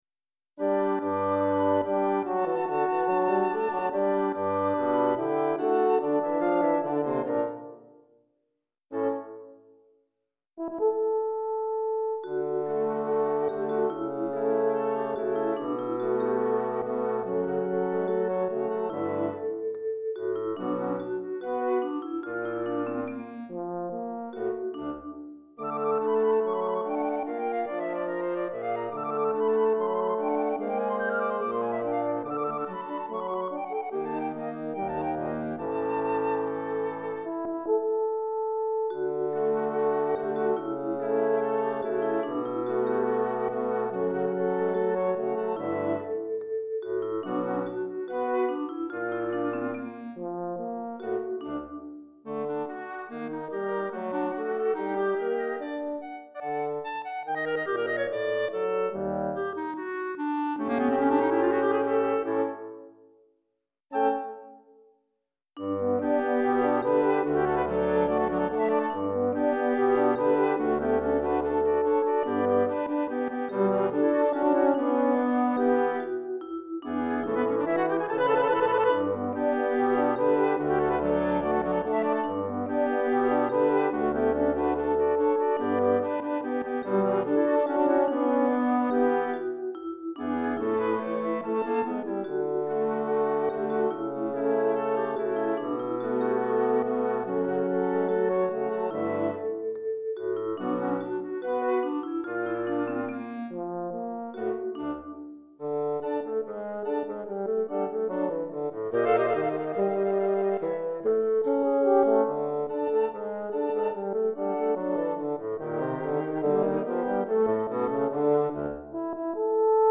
Chorale d'Enfants (10 à 12 ans), Flûte Traversière